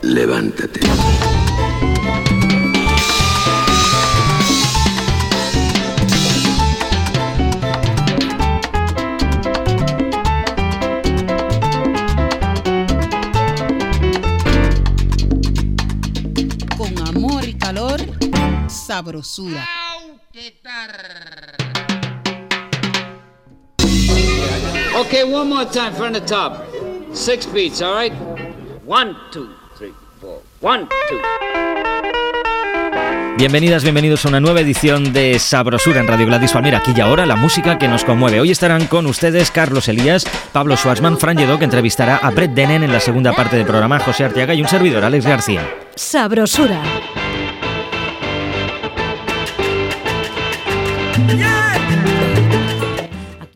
Indicatiu del programa, presentació, equip i tema musical
Musical
FM